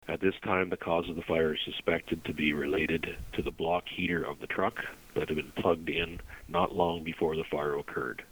The Chief says the cause is believed to be the truck’s block heater- in use for the first time this season.